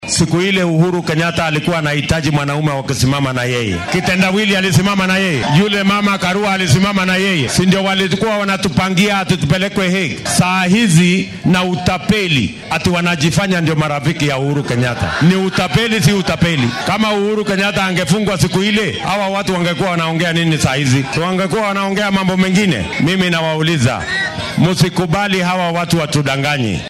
Madaxweyne ku xigeenka dalka ahna musharraxa madaxweyne ee isbeheysiga Kenya Kwanza William Ruto oo isku soo baxyo siyaasadeed ku qabtay Juja, Gatundu iyo deegaano kale oo hoos tago dowlad deegaanka Kiambu ee gobolka bartamaha dalka. Waxaa uu Ruto shacabka halkaasi ku nool uga sheekeeyay garab istaagii siyaasadeed ee uu sanado badan u muujiyay madaxweyne Uhuru Kenyatta xita xilligii ay wada wajahayeen dacwaddii maxkamadda caalamiga ee dembiyada dagaalka ee ICC-da.